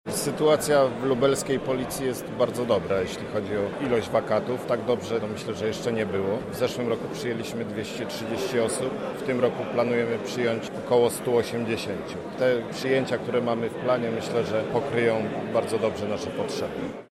Inspektor Paweł Dobrodziej Komendand Wojewódzki Policji w Lublinie zauważa, że jest wielu chętnych i nie ma problemów z obsadzaniem wakatów